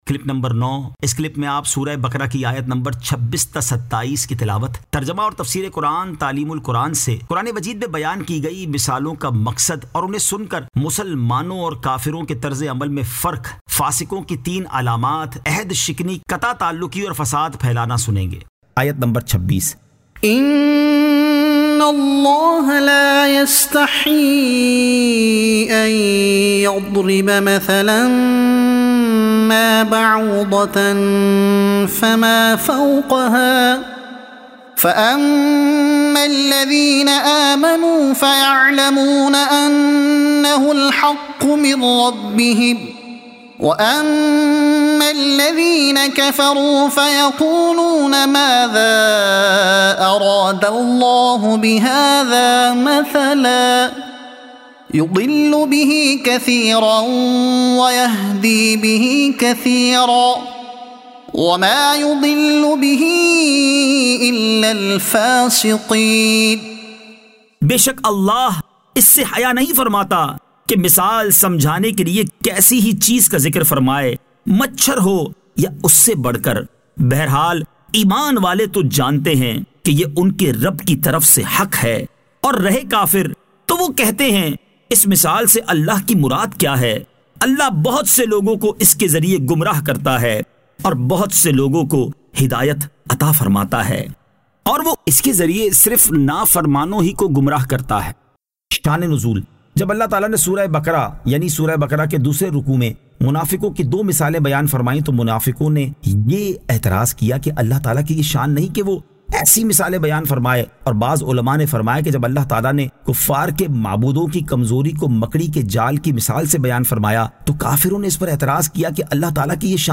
Surah Al-Baqara Ayat 26 To 27 Tilawat , Tarjuma , Tafseer e Taleem ul Quran